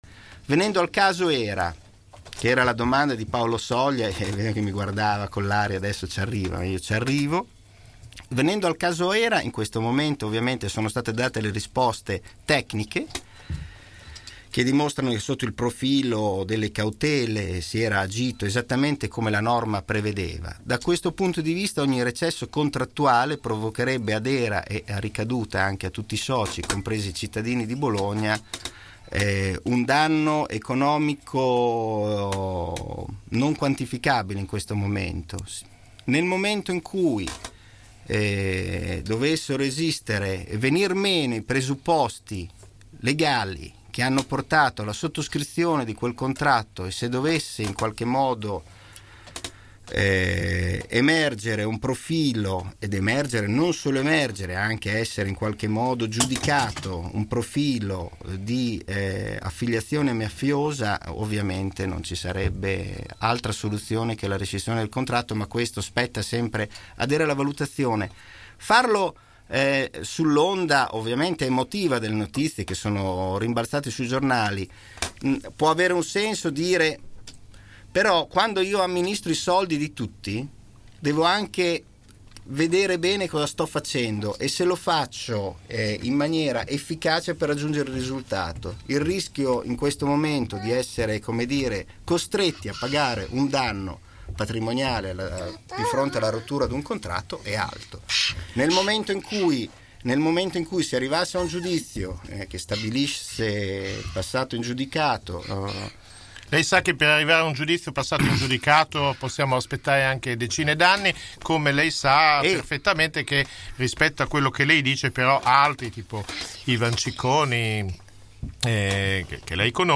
Microfono aperto stamattina in radio con il  Vicesindaco Claudio Merighi rimasto vittima, come altre migliaia di bolognesi, del traffico in tilt.